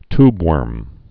(tbwûrm, tyb-)